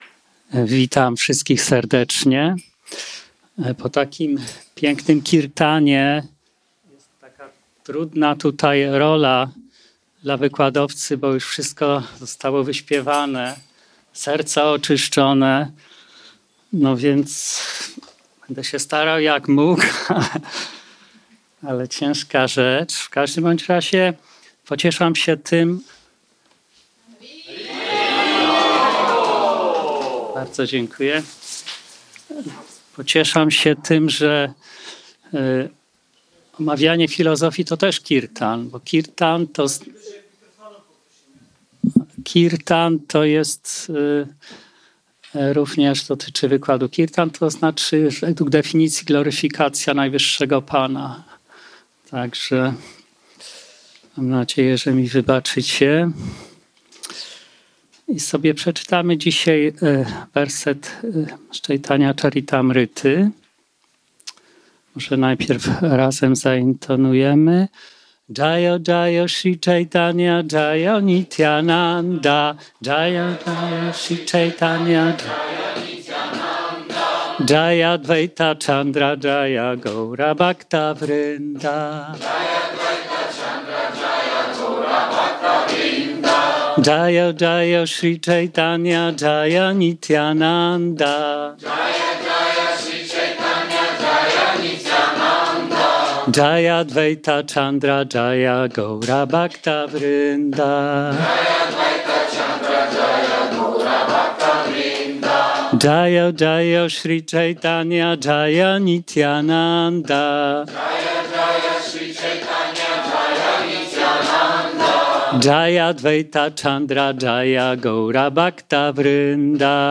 Wykład z okazji święta Gaura Purnima z Caitanya Caritamrta Adi-lila 2.1